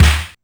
Snare3.aif